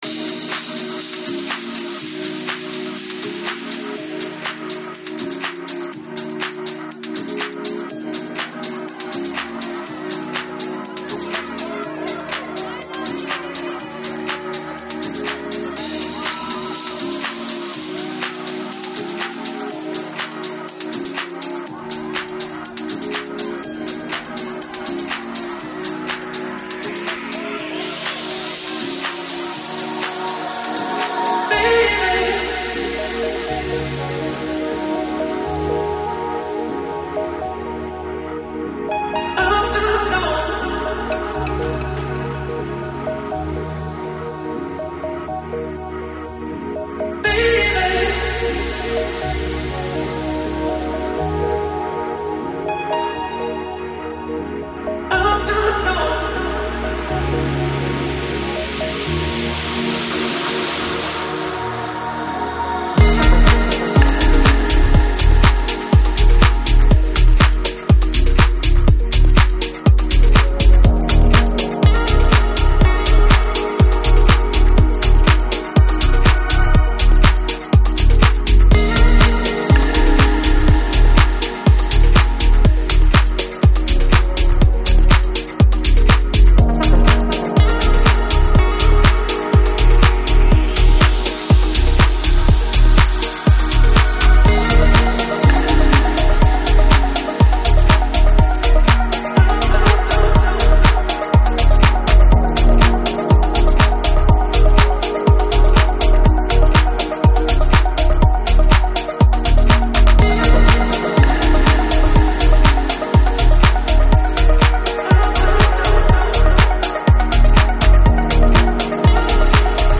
Жанр: Progressive House, Organic House, Deep House